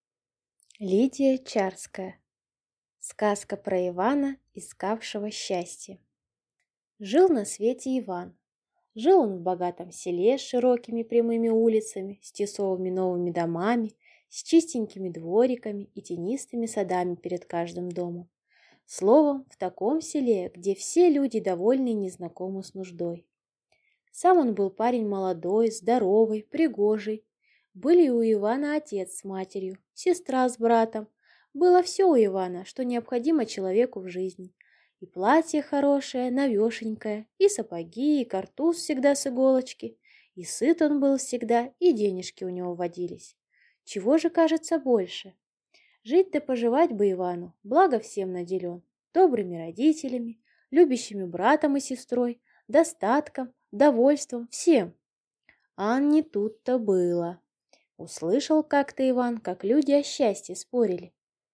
Аудиокнига Сказка про Ивана, искавшего счастье | Библиотека аудиокниг